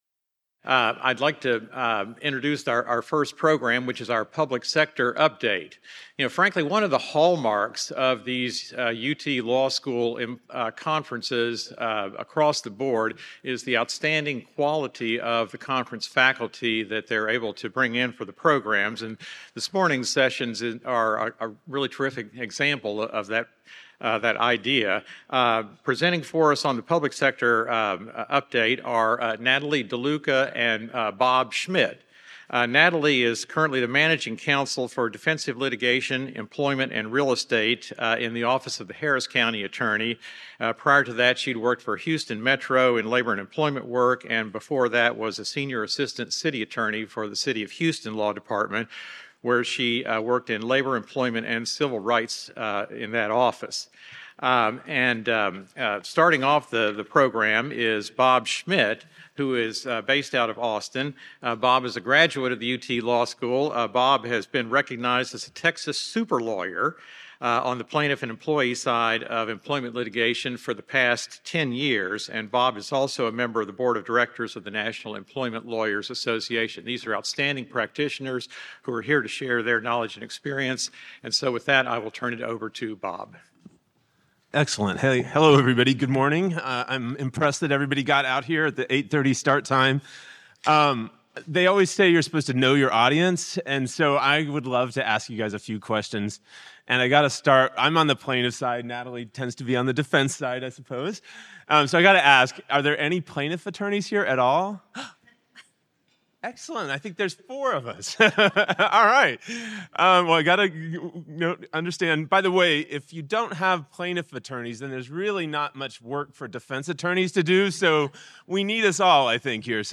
Originally presented: May 2024 Labor and Employment Law Conference